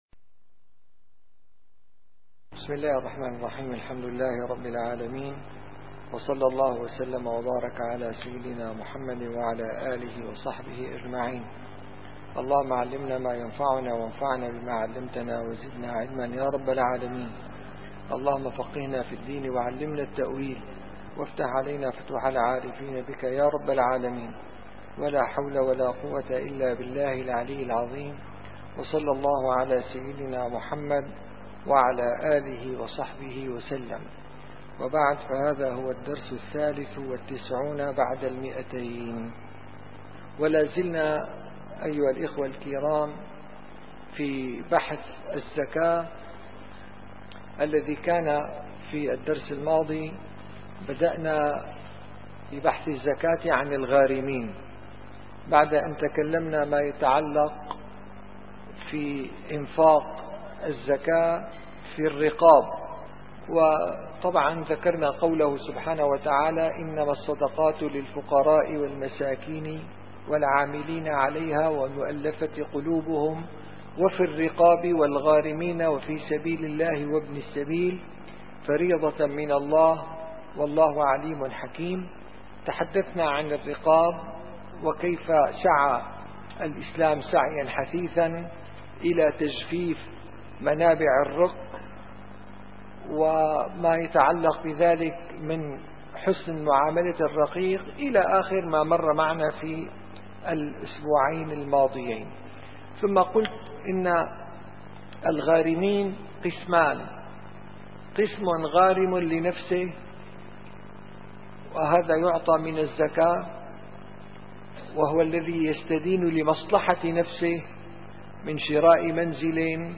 - الدروس العلمية - شرح صحيح البخاري - كتاب الزكاة الغارم لمصلحة المجتمع